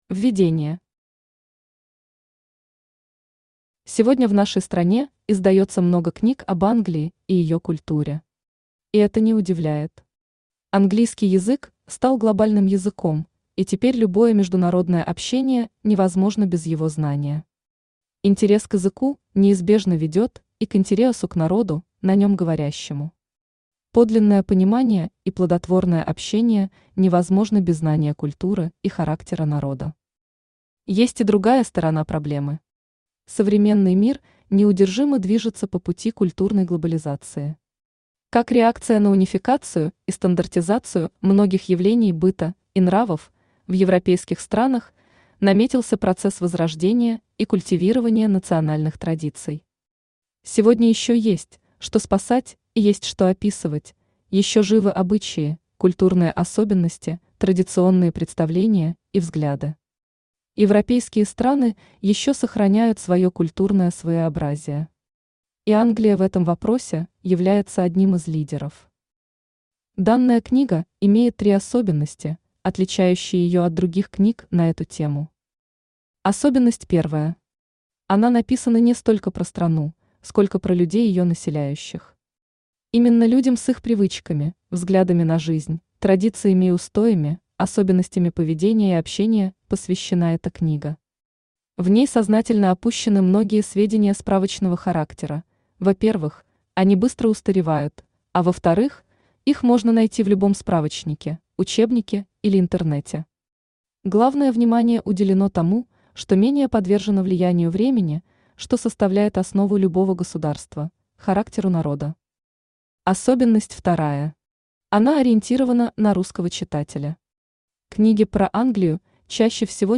Аудиокнига Англия и англичане | Библиотека аудиокниг
Aудиокнига Англия и англичане Автор Анна Павловская Читает аудиокнигу Авточтец ЛитРес.